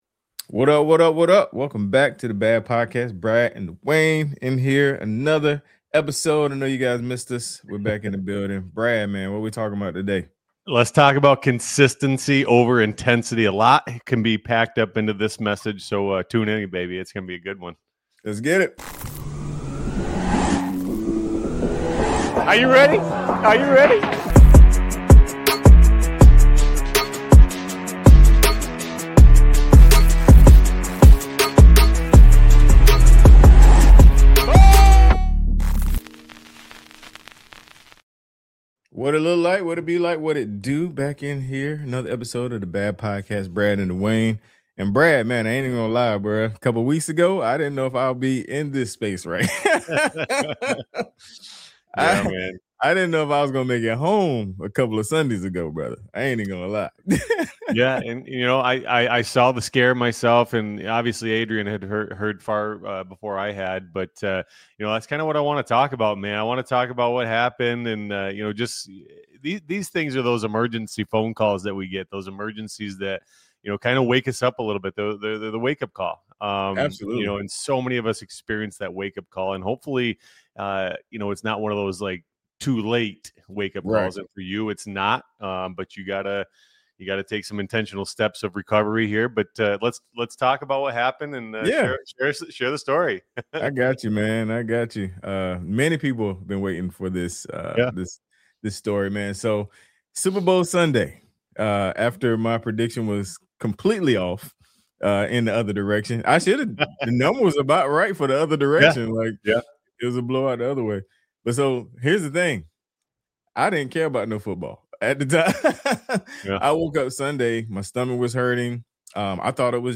E2M Fitness Media Network is a fitness podcast with various shows. Interview style and round table discussion with physicians. Also, entertaining talk show style, motivating episodes with trainers, and more.